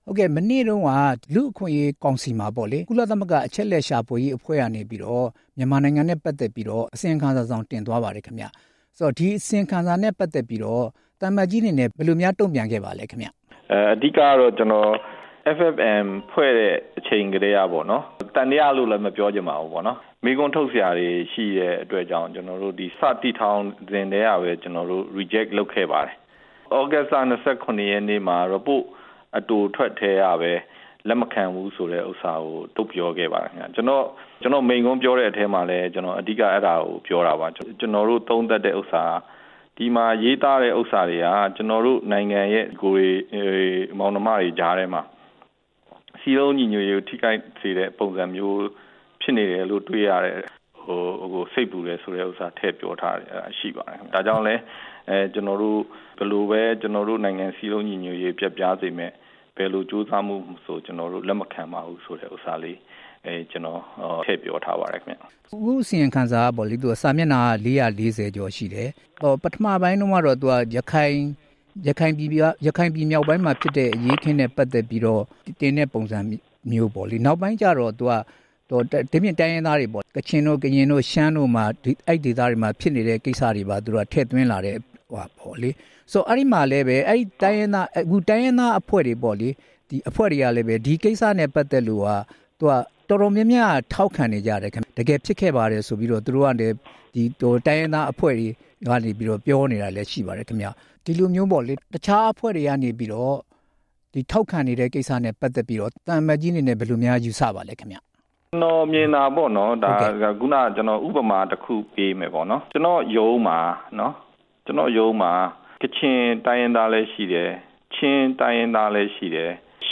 ကုလ အစီရင်ခံစာကိစ္စ မြန်မာအမြဲတမ်းကိုယ်စားလှယ်နဲ့ ဆက်သွယ်မေးမြန်းချက်